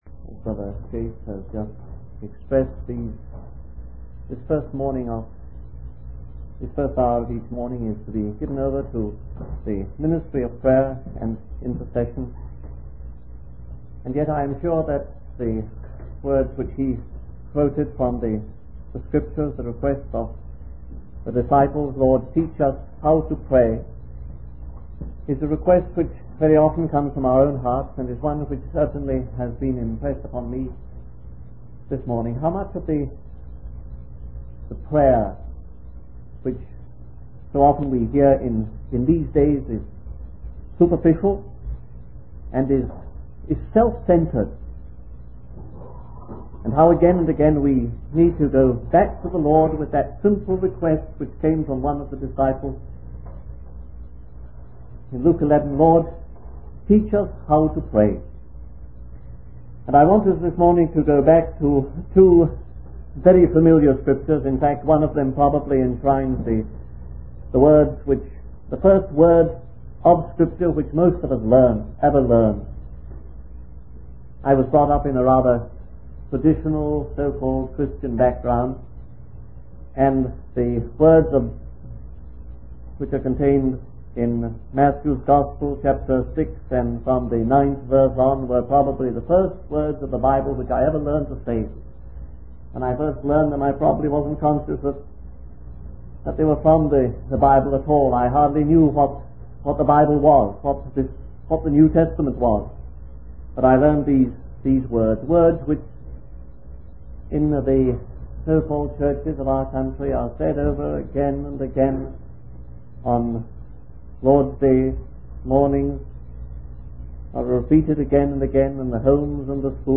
In this sermon, the speaker focuses on the importance of prayer and the early church's prayer meetings.